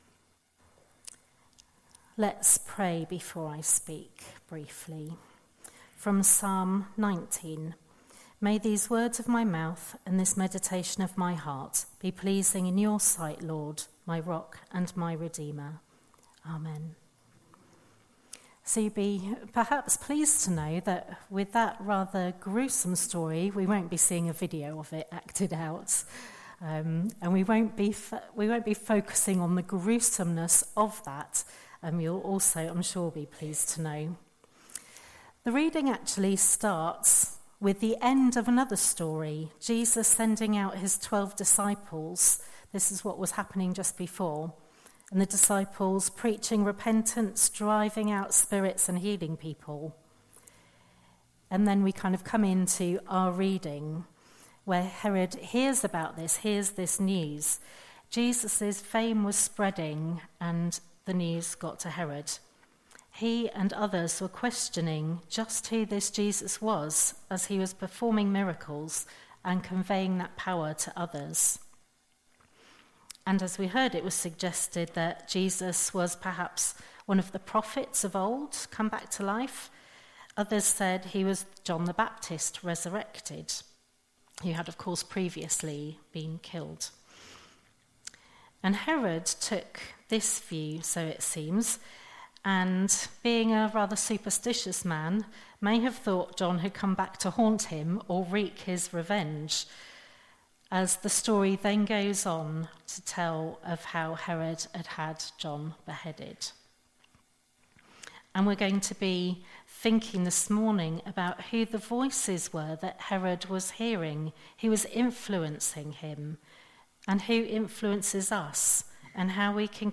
An audio file of the sermon is also available.
07-14-sermon.mp3